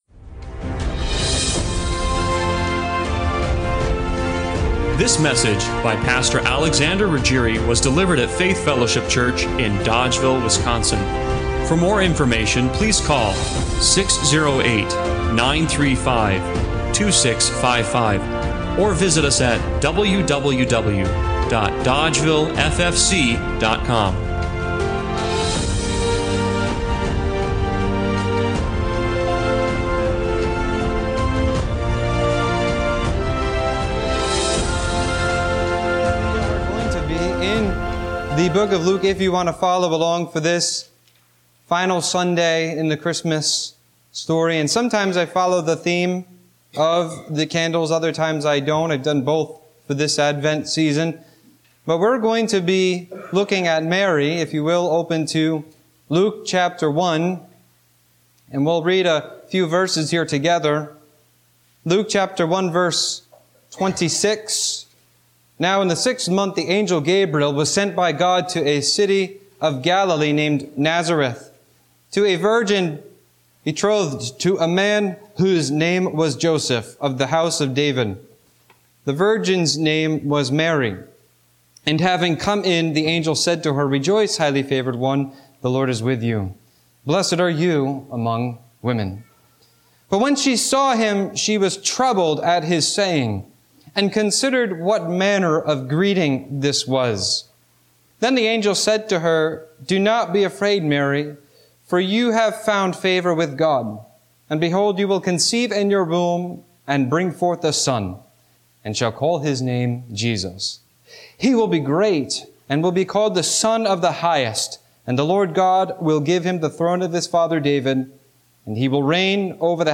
Luke 1:26–39 Service Type: Sunday Morning Worship During the Christmas season